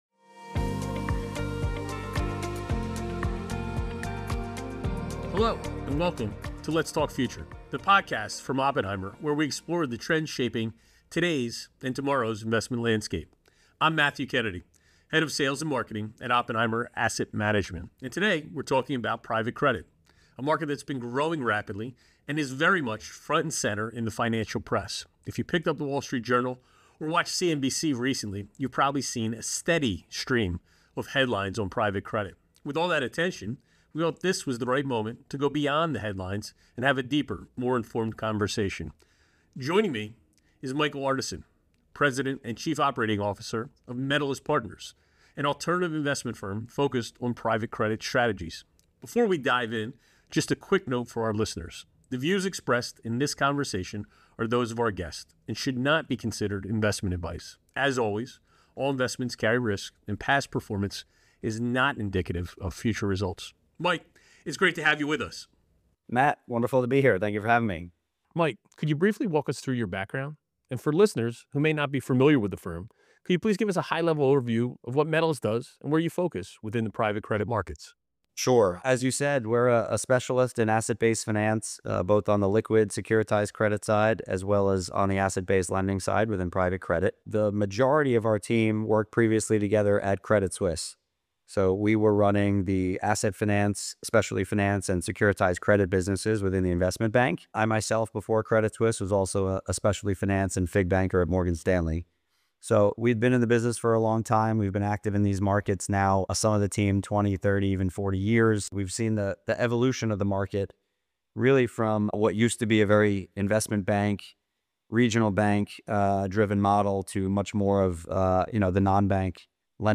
A Podcast Conversation